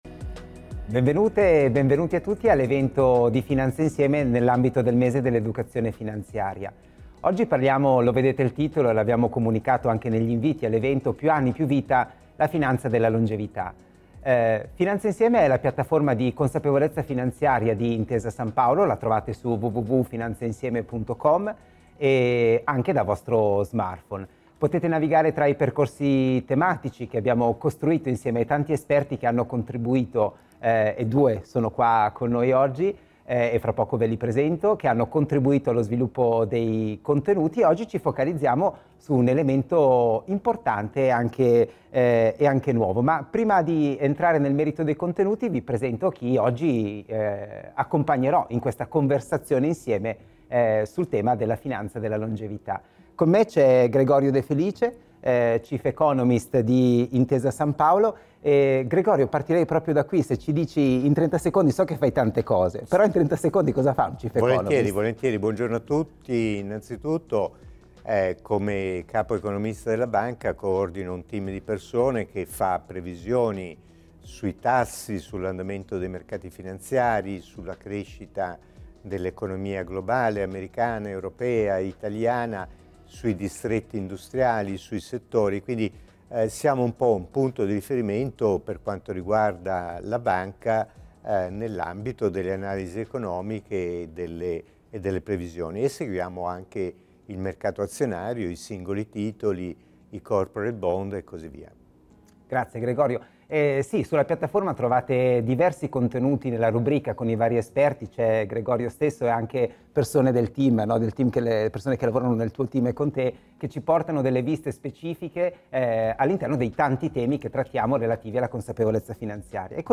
Rivivi l’evento live di Finanza Insieme realizzato per il Mese dell’Educazione Finanziaria 2025